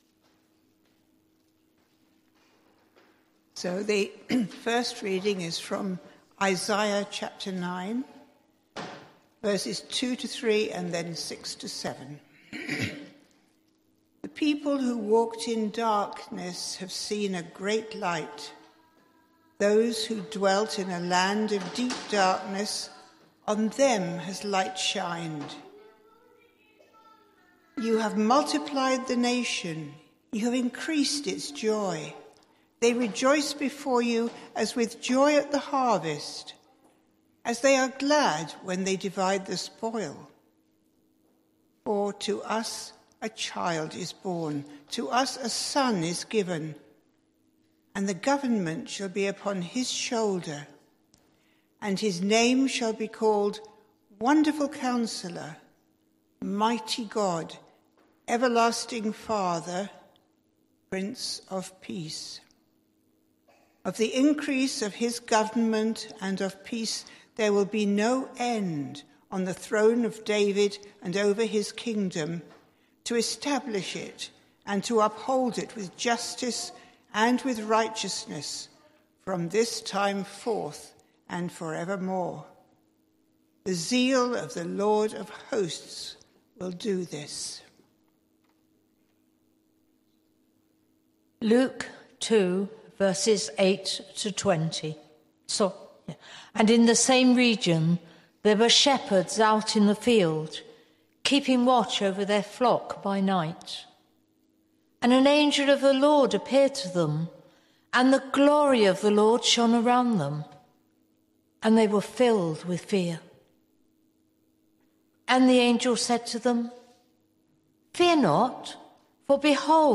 Sermon Series: Advent